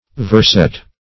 verset - definition of verset - synonyms, pronunciation, spelling from Free Dictionary Search Result for " verset" : The Collaborative International Dictionary of English v.0.48: Verset \Vers"et\ (v[~e]rs"[e^]t), n. [F.]